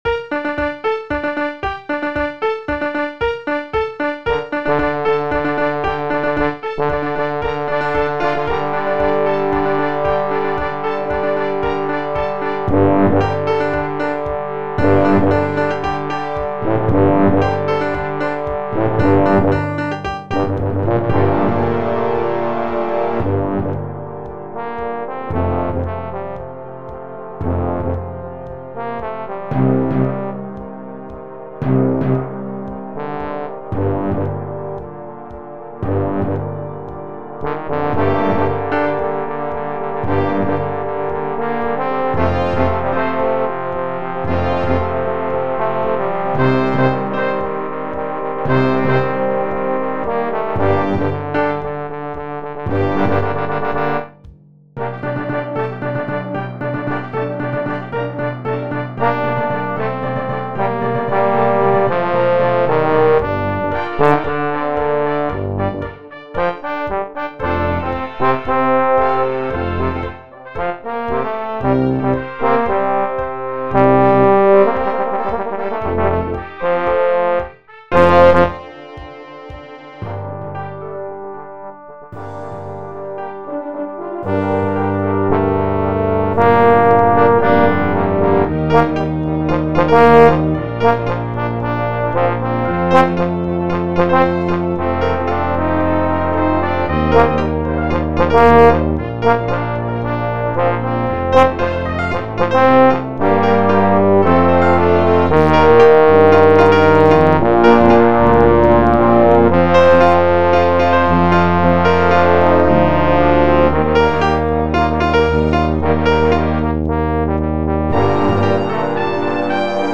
Posaune
mp3 (elektronisch generierte Audiodatei)